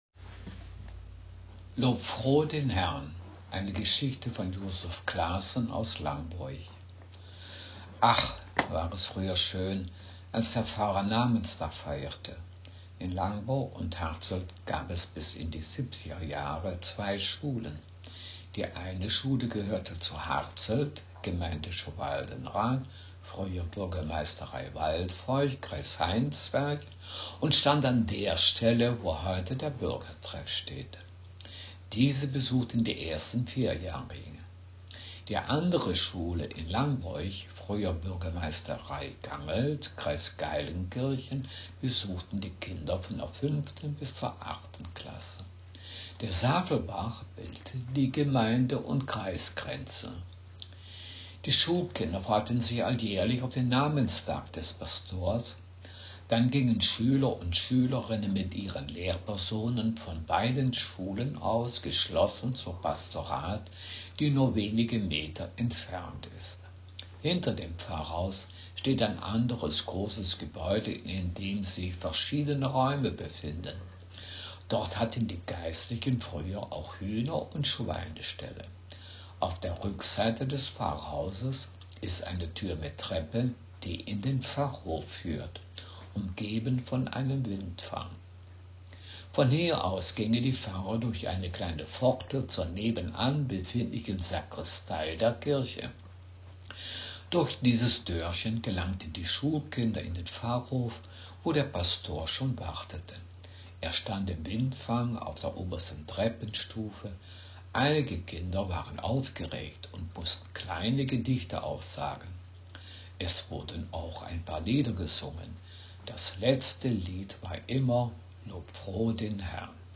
Text hochdeutsch